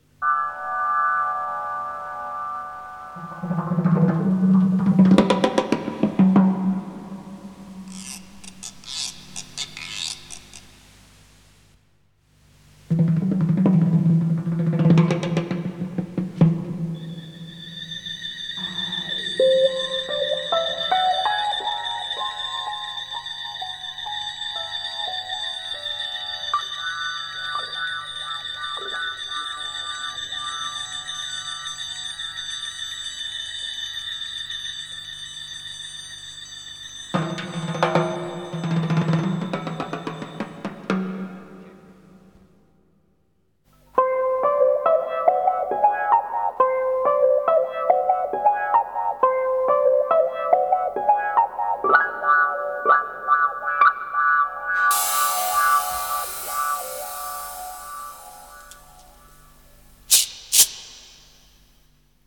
Incidental Music